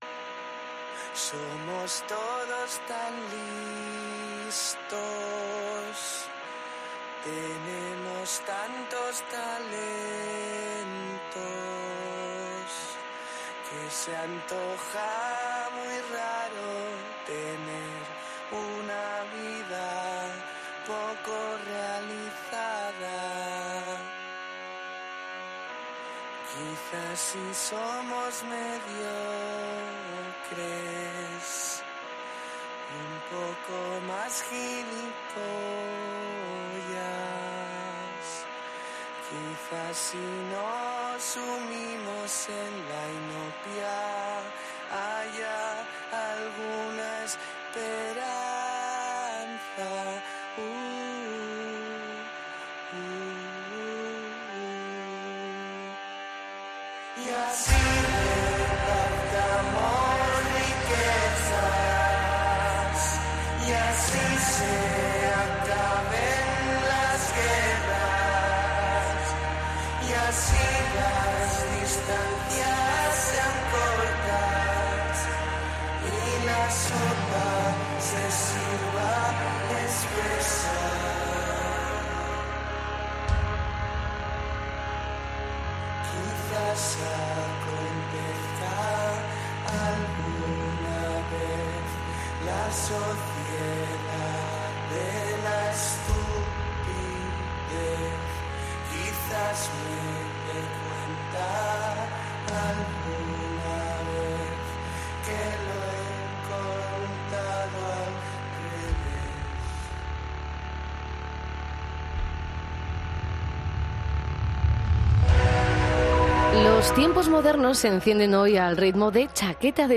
Entrevista a Chaqueta de Chandal en los Tiempos Modernos